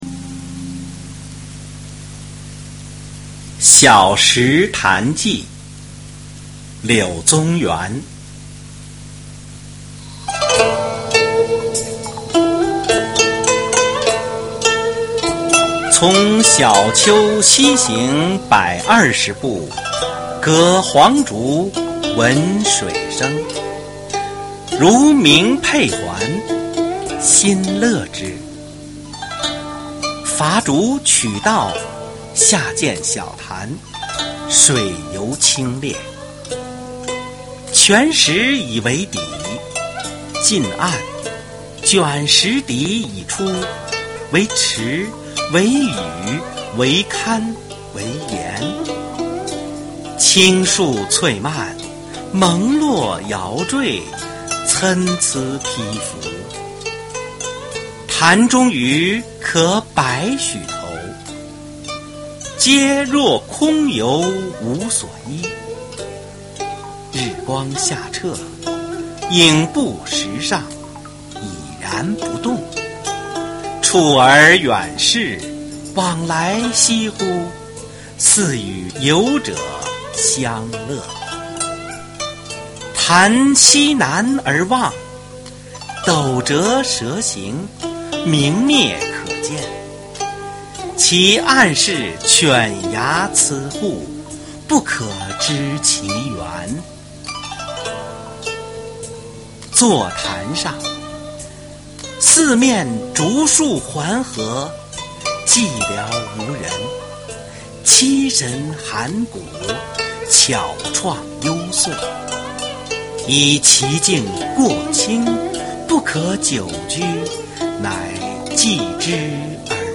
《小石潭记》原文及译文（含朗读）　/ 柳宗元